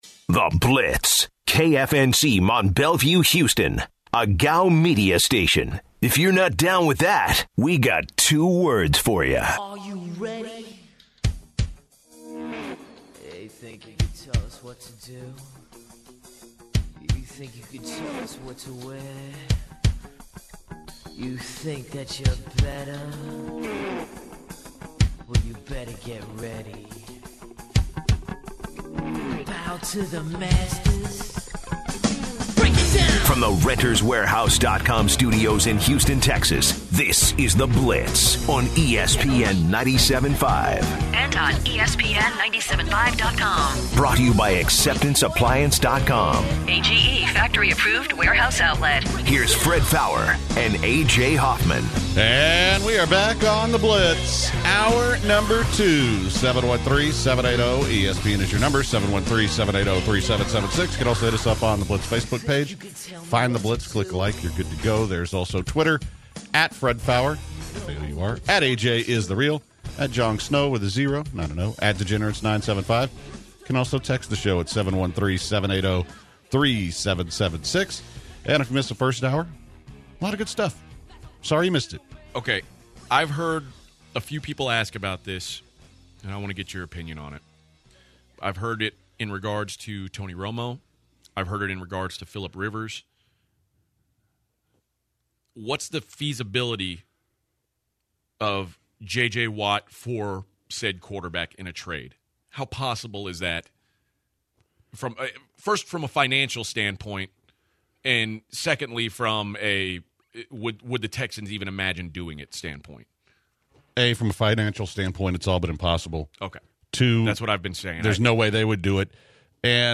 To begin the hour they continue to talk about the Houston Texans and their projected roster next year. They also recap all the playoff games from last week and give their super bowl predictions. Former Raiders CB Stanford Routt joins the program in studio and also the Zadok Jewelers Gem of the Day.